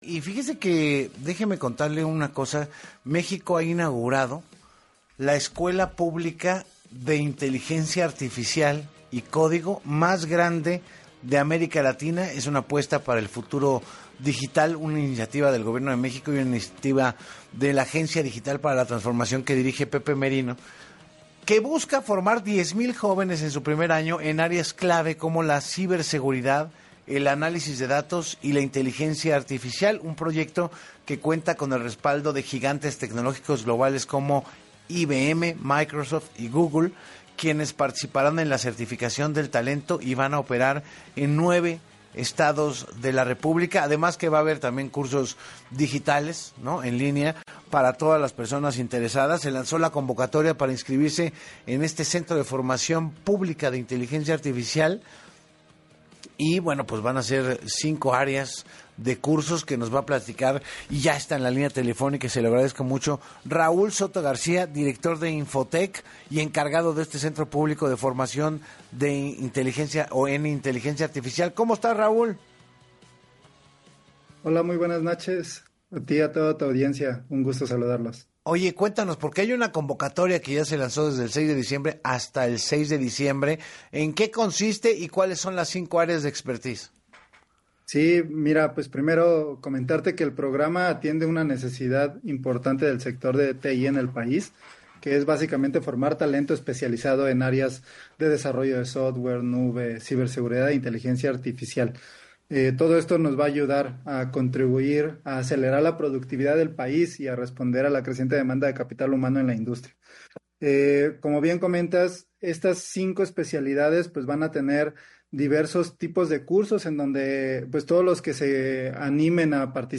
En entrevista